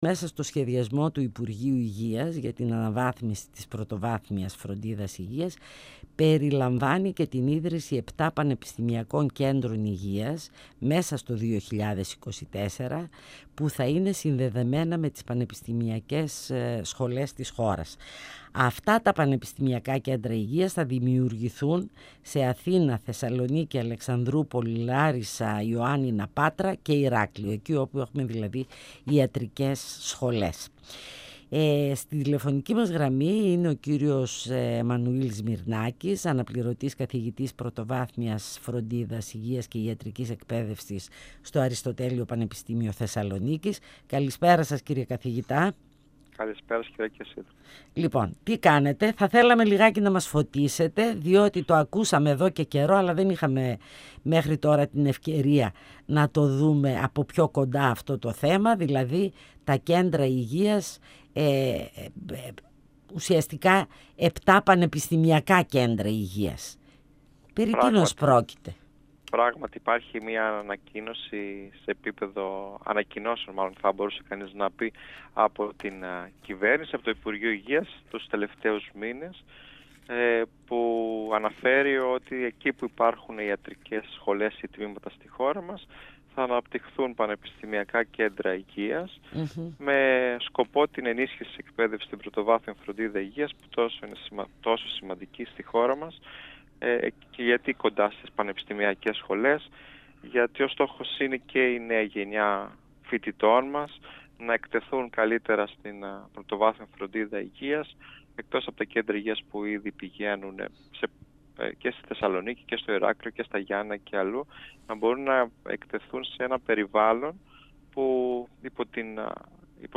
Φωνες Πισω απο τη Μασκα Συνεντεύξεις